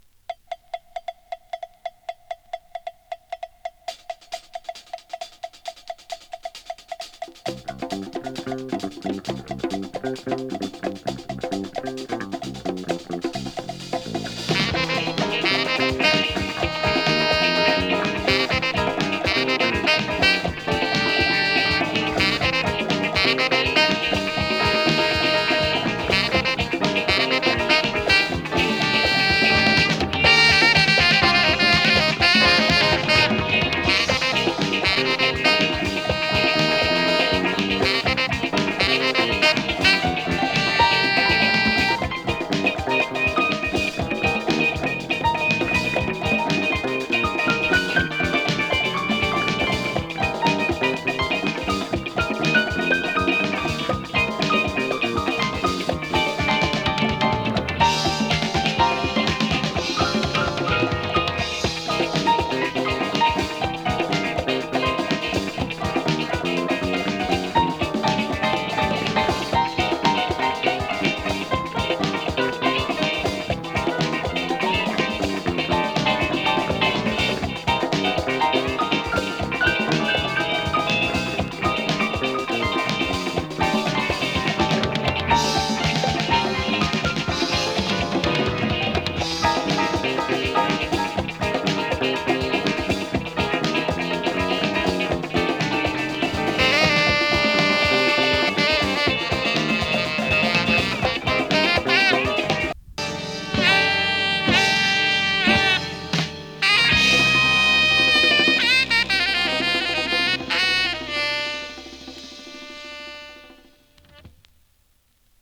終盤にドラム・ブレイクも忍ばせた